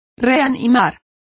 Complete with pronunciation of the translation of resuscitate.